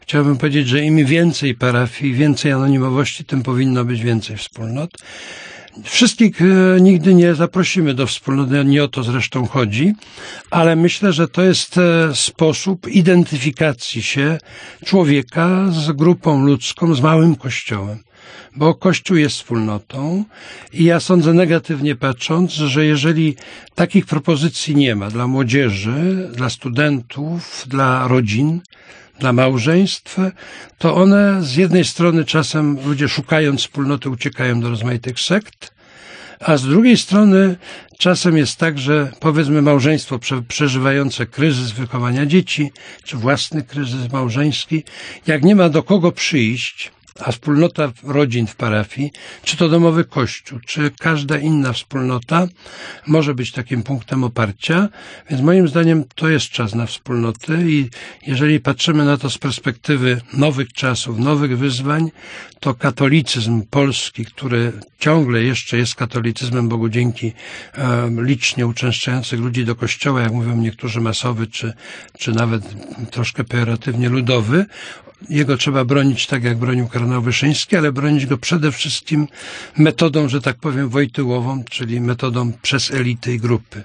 Wspólnoty katolickie są sposobem na identyfikację współczesnego człowieka z grupą ludzką i Kościołem. W trudnych momentach lub czasie kryzysu mogą być ważnym oparciem dla młodzieży, studentów, rodzin i małżeństw - mówił w wywiadzie dla Radia Plus abp Kazimierz Nycz.
Posłuchaj wypowiedzi arcybiskupa Nycza » Nagranie pochodzi z programu "Twarzą w twarz" (Radio Plus) z 21.01.2009